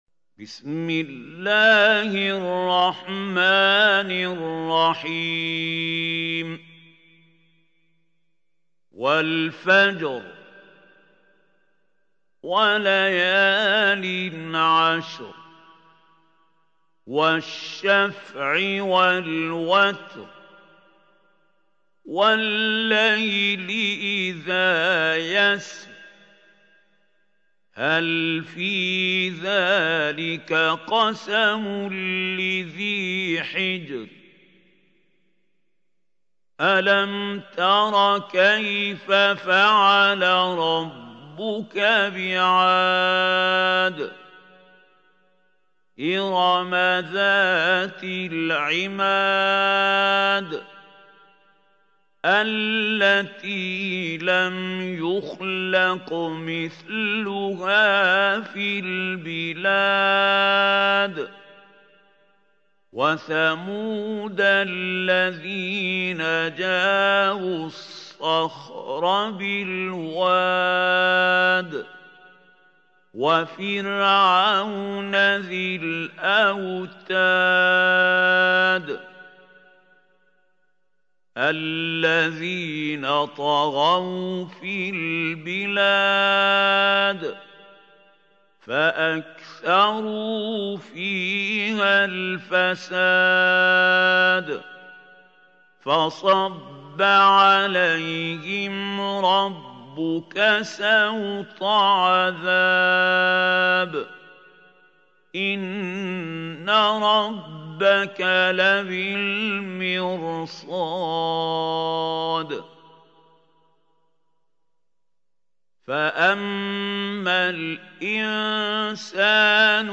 سورة الفجر | القارئ محمود خليل الحصري